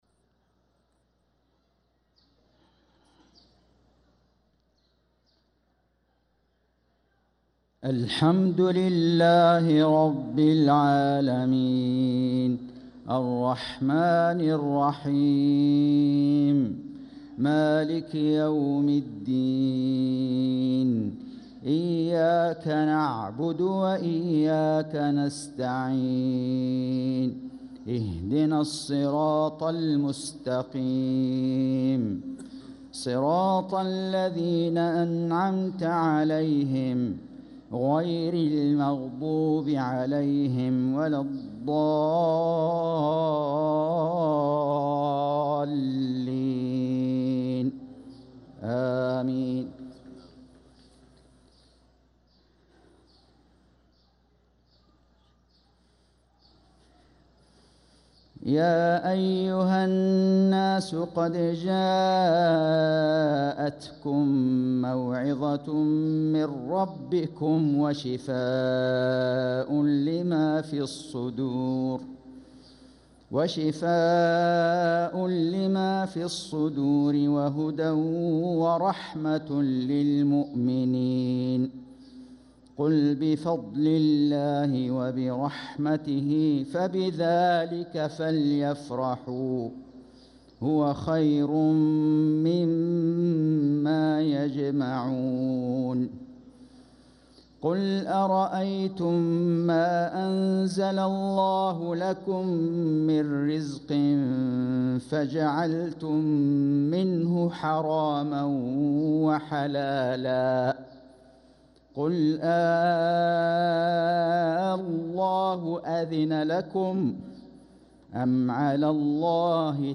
صلاة المغرب 2-8-1446هـ | من سورة يونس 57-65 | Maghrib prayer from Surah Yunus | 1-2-2025 > 1446 🕋 > الفروض - تلاوات الحرمين